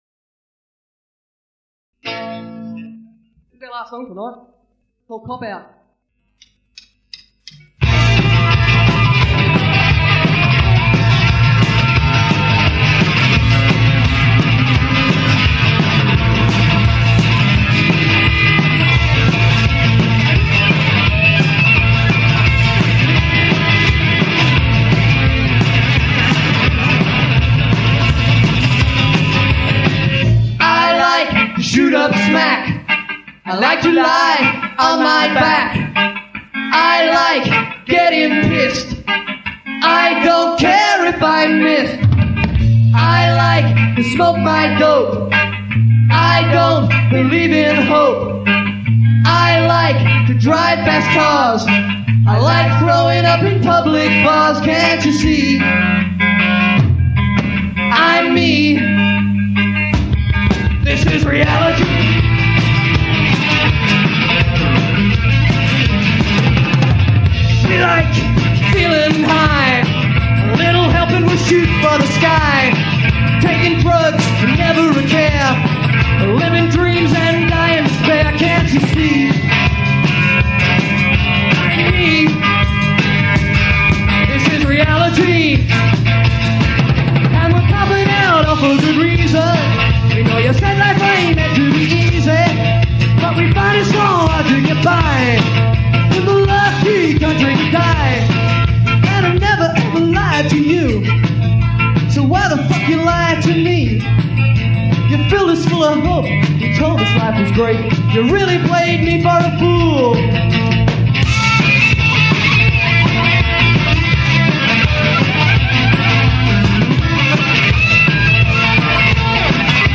lead guitar/vocals
drums/sound
live